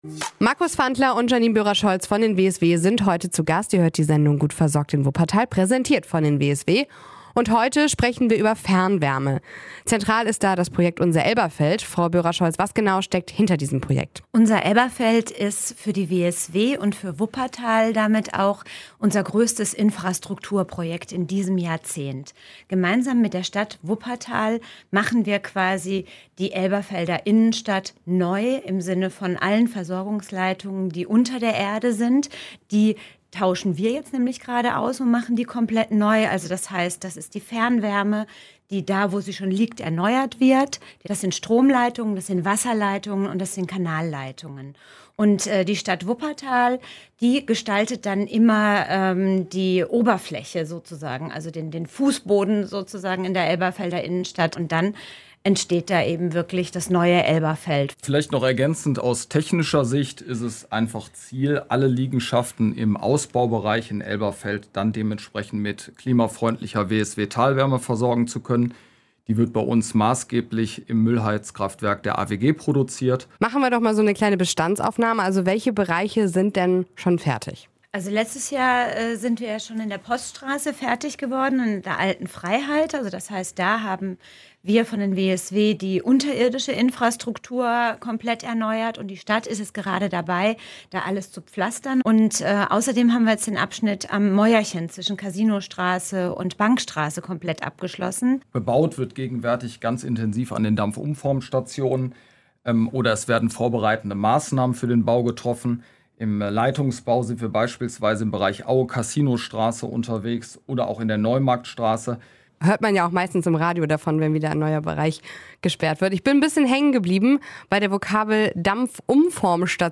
Das ist Thema in der Sondersendung WSW Gut versorgt in Wuppertal.